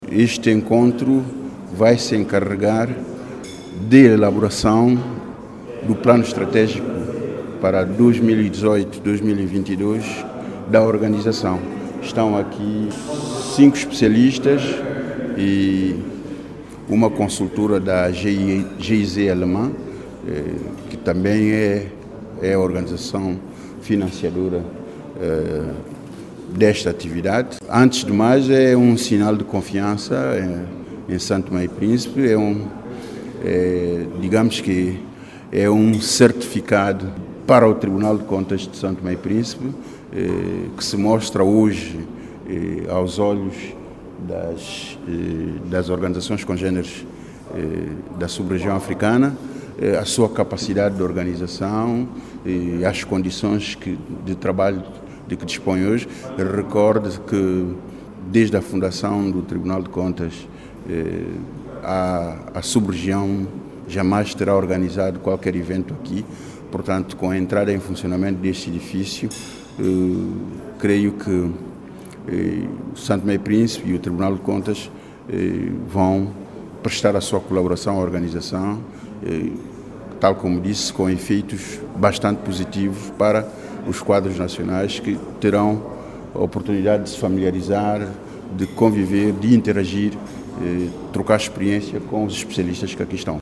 Ouça Presidente do Tribunal de Contas, José António Monte Cristo